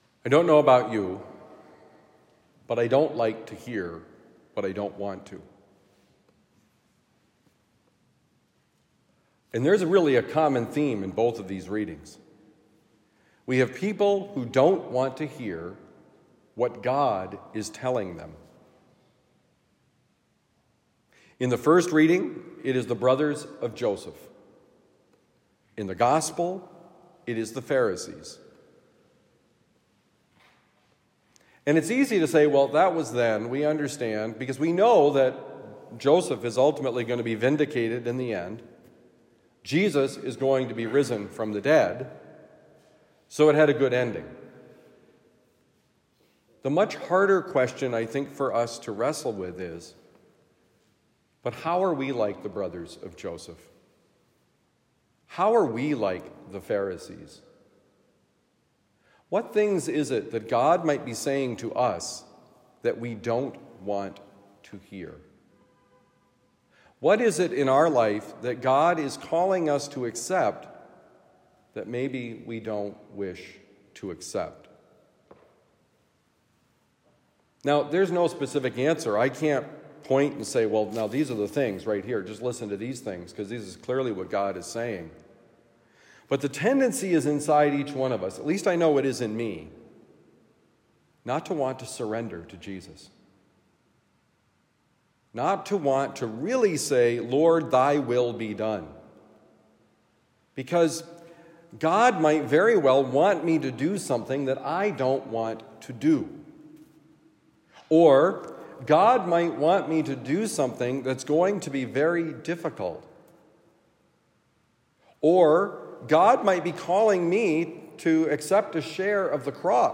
You’re not the boss of me: Homily for Friday, March 1, 2024